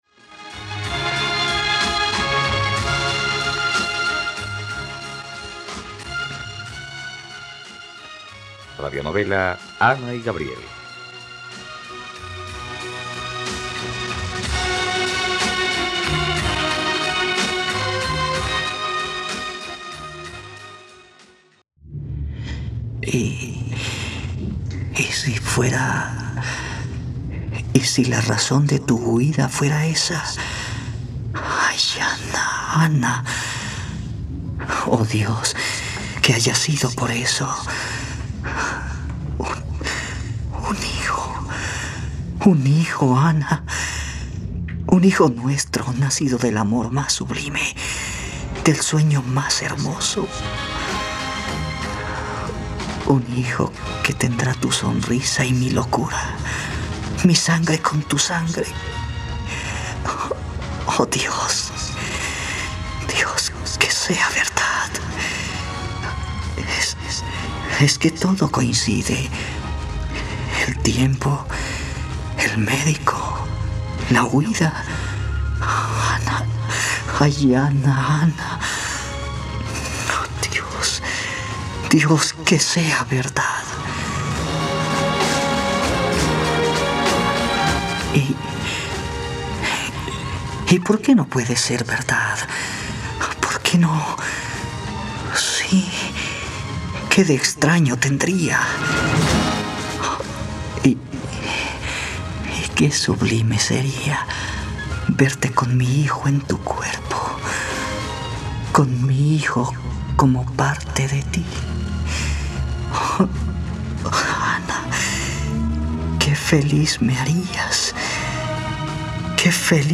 ..Radionovela. Escucha ahora el capítulo 108 de la historia de amor de Ana y Gabriel en la plataforma de streaming de los colombianos: RTVCPlay.